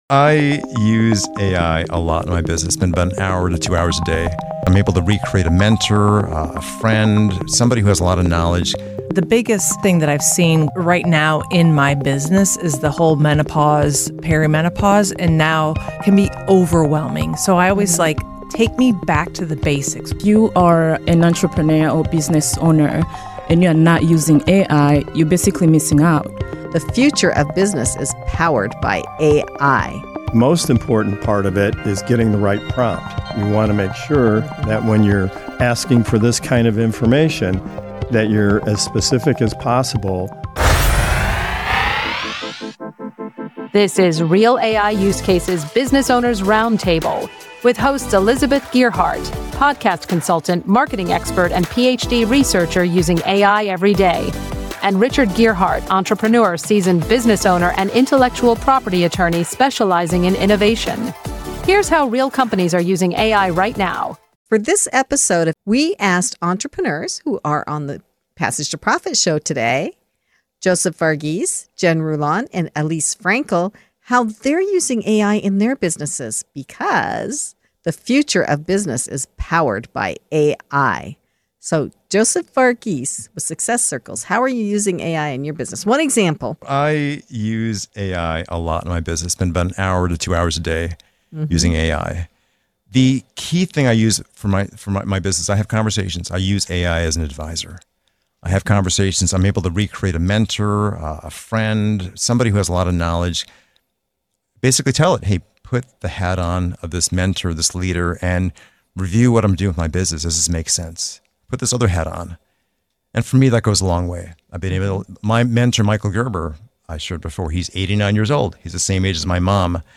Real AI Use Cases Business Owners Roundtable Why Your Business Can't Ignore AI